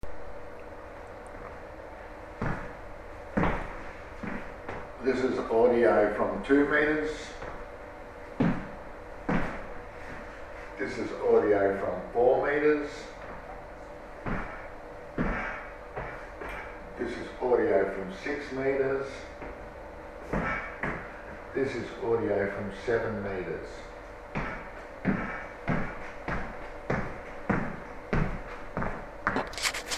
AFOMR150-Micro-Magnetic-Black-Box-Voice-Recorder-Sample-Audio.mp3